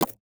UIMvmt_Menu_Slide_Next_Page_Close 01.wav